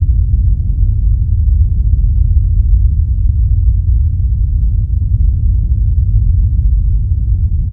quake.wav